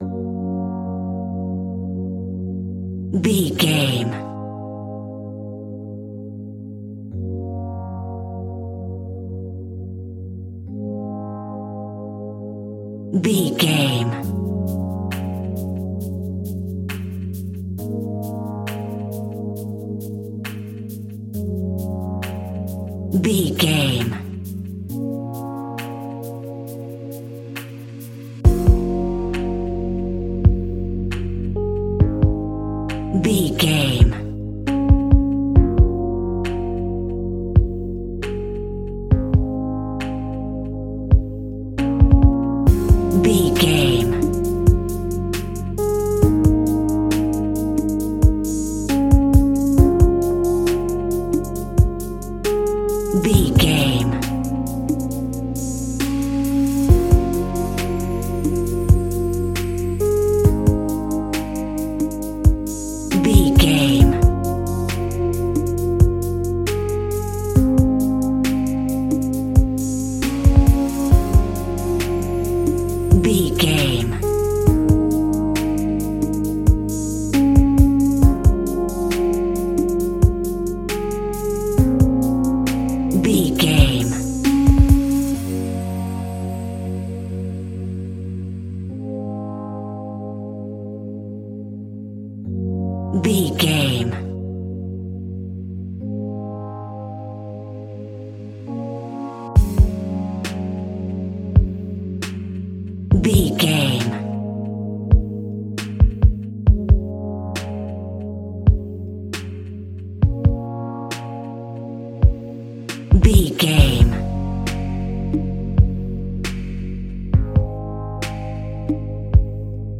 Aeolian/Minor
B♭
Slow
laid back
groove
hip hop drums
hip hop synths
piano
hip hop pads